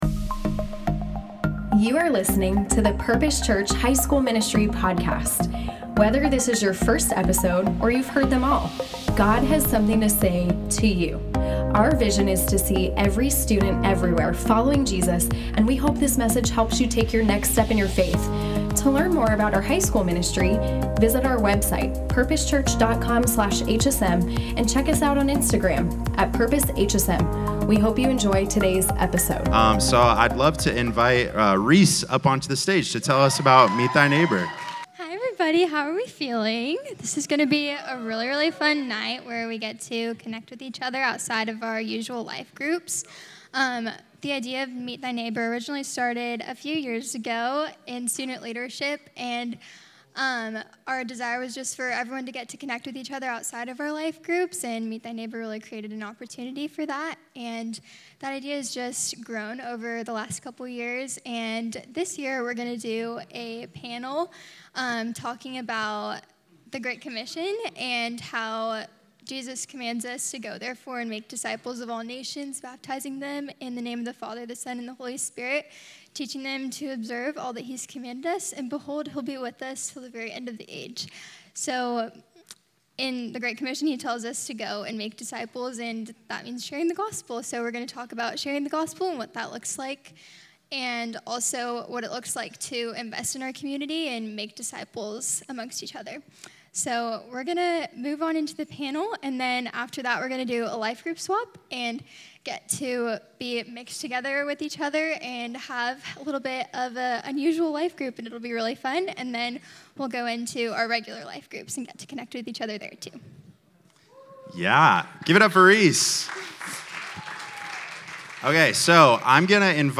Meet Thy Neighbor: Q&A Panel - Purpose Church Media
Tonight we got the chance to hear from an inspiring panel of students and leaders who are deeply passionate about being in community and sharing the gospel with others!
meet-thy-neighbor-qa-panel.mp3